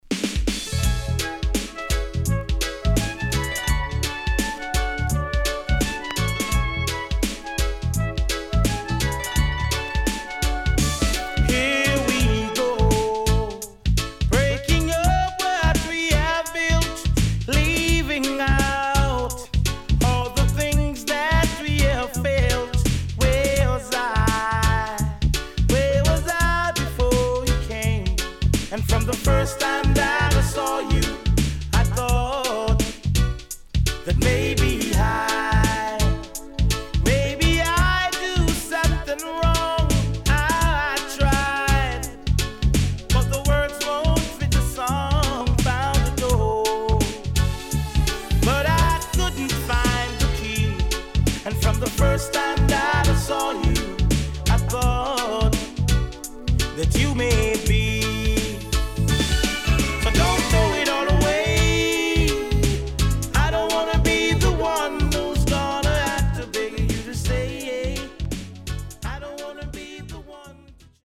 名曲Cover Club Mix